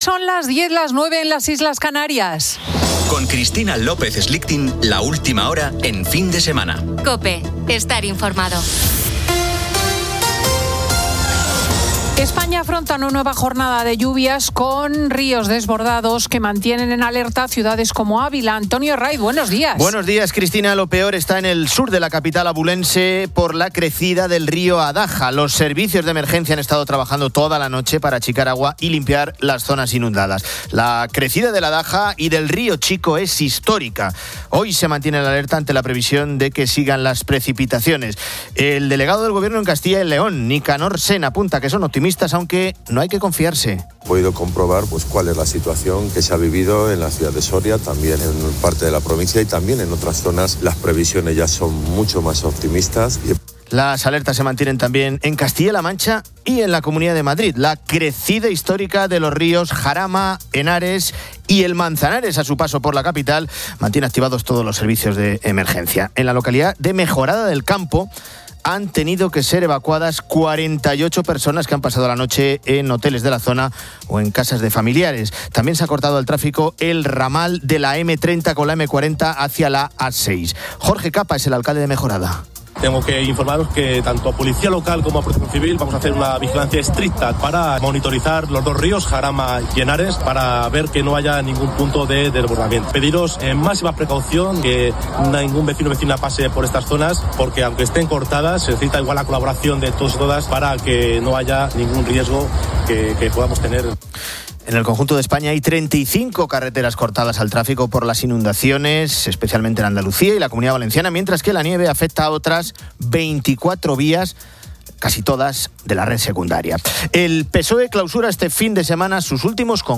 Editorial de Cristina López Schlichting.
Entrevista a Mar Galcerán, diputada del PP en las Cortes Valencianas con Síndrome de Down. Marian Rojas habla de las pantallas en los colegios, una relación difícil.